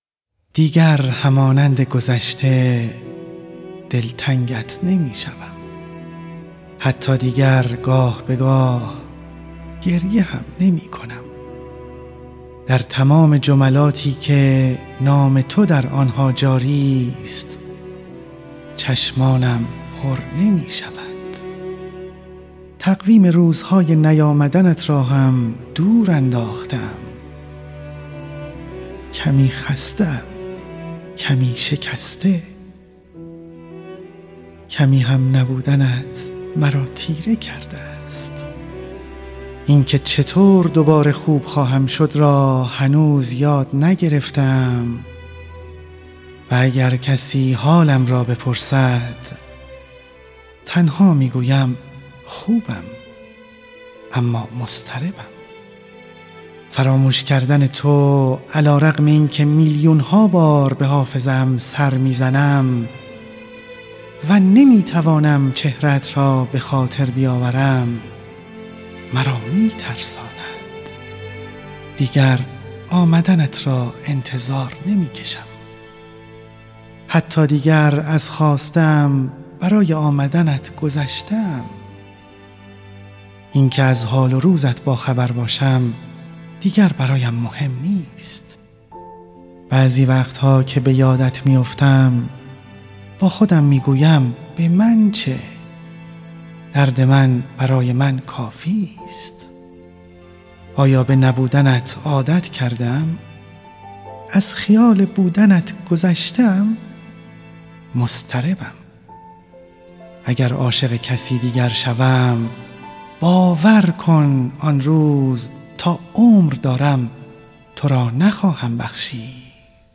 در این مجموعه سروده‌هایی مهم در ادبیات ایران و جهان انتخاب شده و با همراهی موسیقی خوانده شده‌اند.
هر اجرا هم با موسیقی متناسبی همراه شده است.